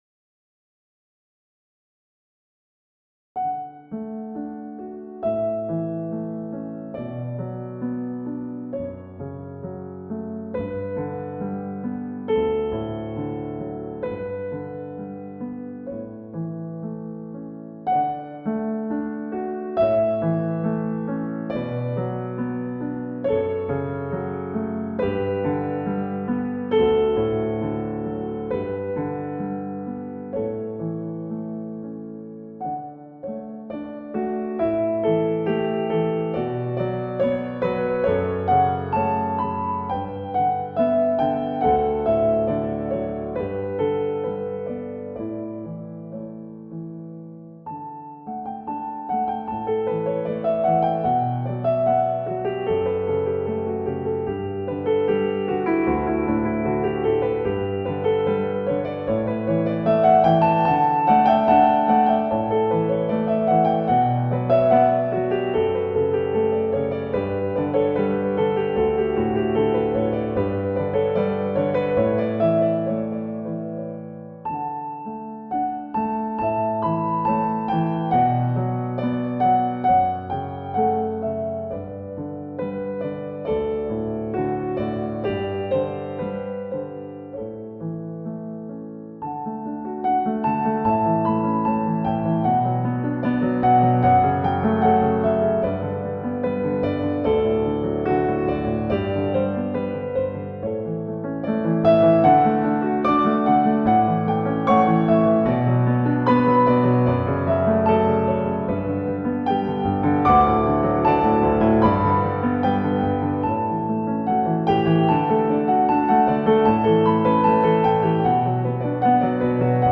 Posłuchaj utworu w ciekawej aranżacji na instrument klawiszowy: